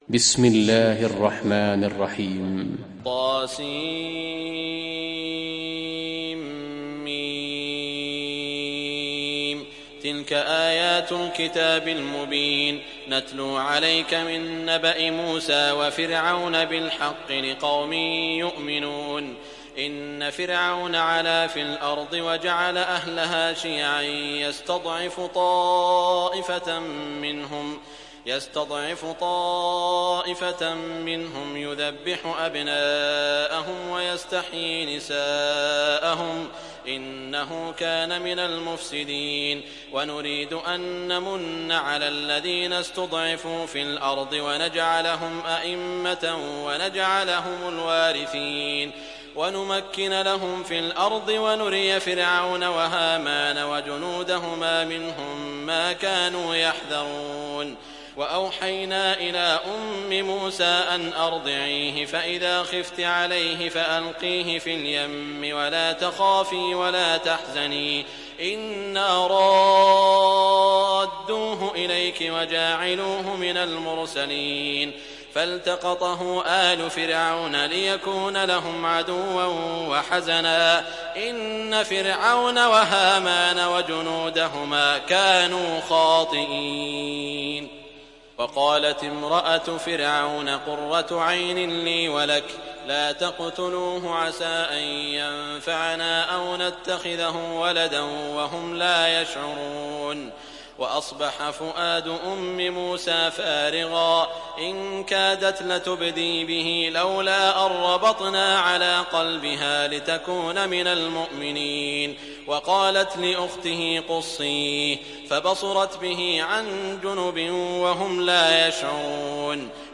Surat Al Qasas mp3 Download Saud Al Shuraim (Riwayat Hafs)